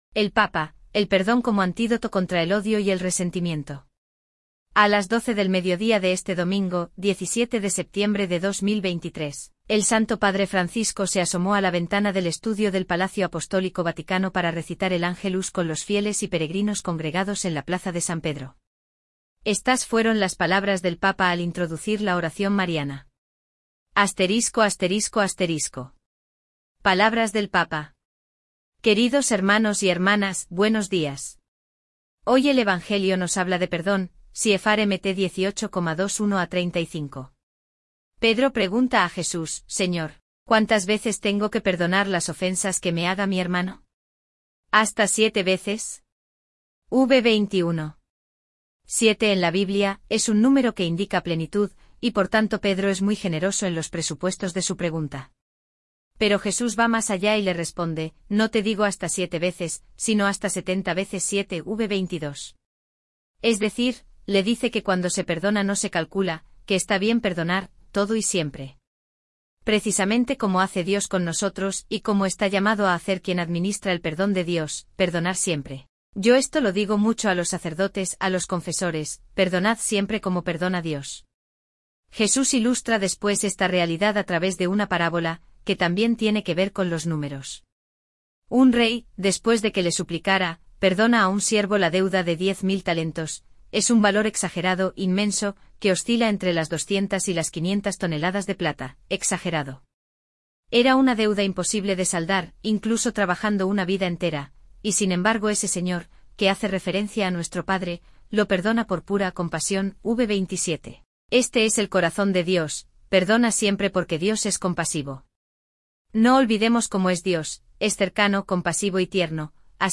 Palabras del Santo Padre antes del Ángelus
A las 12 del mediodía de este domingo, 17 de septiembre de 2023,  el Santo Padre Francisco se asomó a la ventana del estudio del Palacio Apostólico Vaticano para recitar el Ángelus con los fieles y peregrinos congregados en la Plaza de San Pedro.